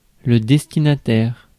Ääntäminen
IPA: /dɛs.ti.na.tɛʁ/